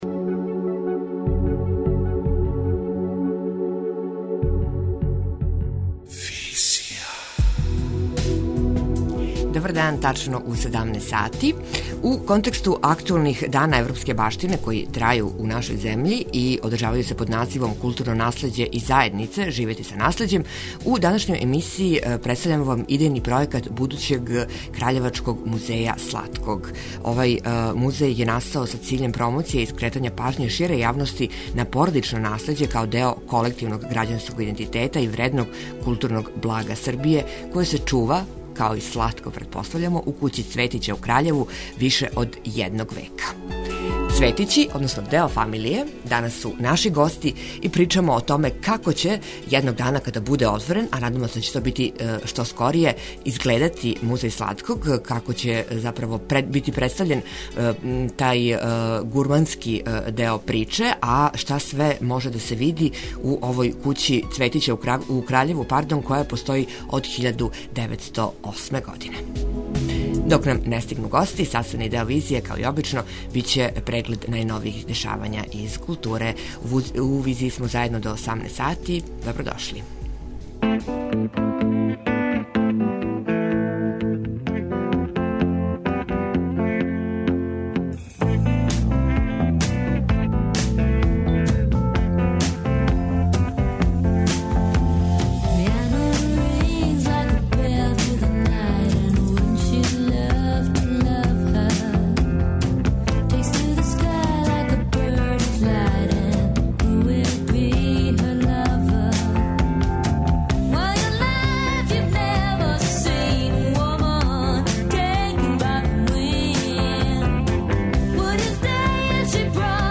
преузми : 27.32 MB Визија Autor: Београд 202 Социо-културолошки магазин, који прати савремене друштвене феномене.